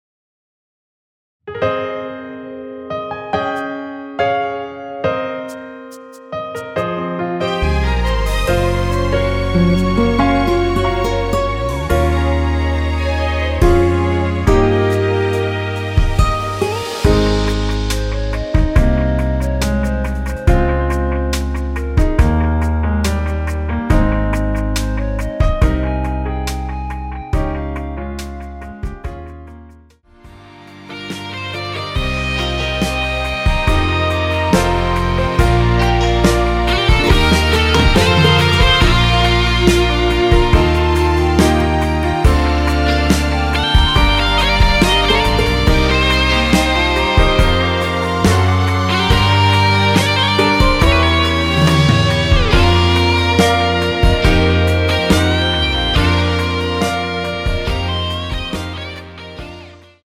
원키 멜로디 포함된 MR입니다.
Ab
앞부분30초, 뒷부분30초씩 편집해서 올려 드리고 있습니다.